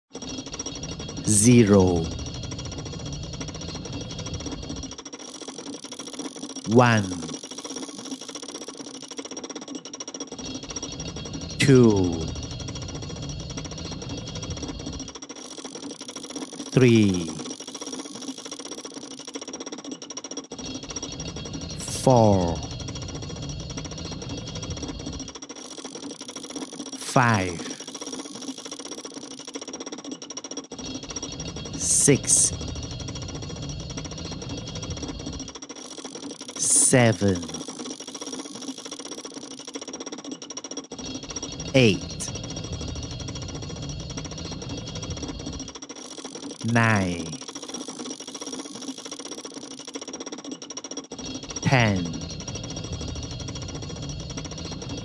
Perforated metal number with gear